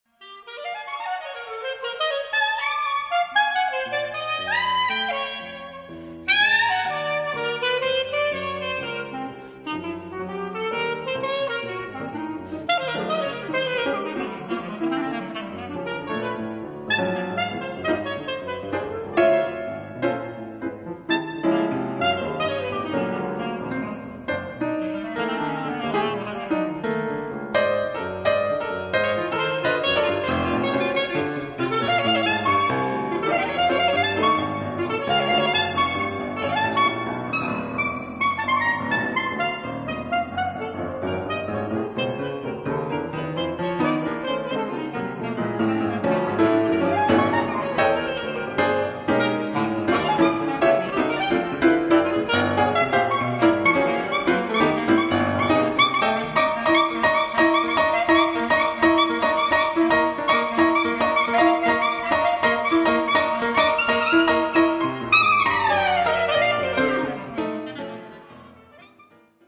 クラリネットとの共演、意外に聴きやすいゴリゴリ現代音楽コンサート
piano
basset clarinet